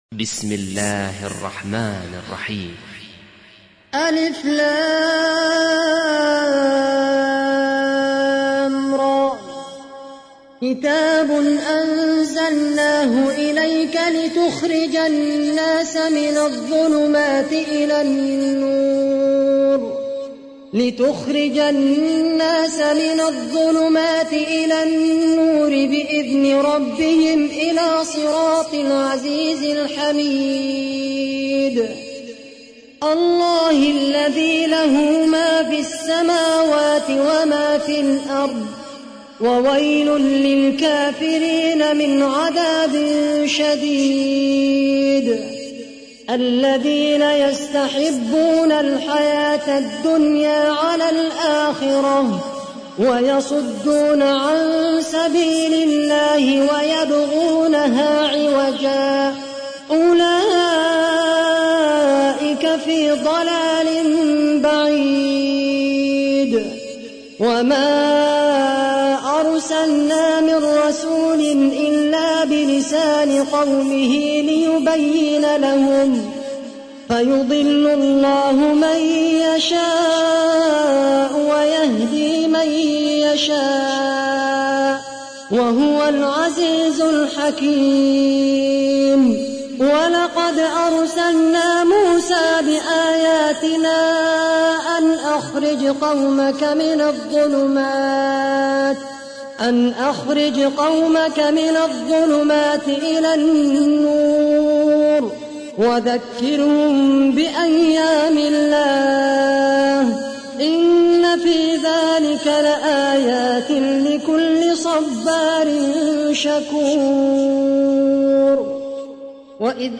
تحميل : 14. سورة إبراهيم / القارئ خالد القحطاني / القرآن الكريم / موقع يا حسين